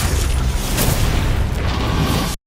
HIT 13.wav